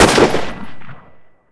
WR_fire.wav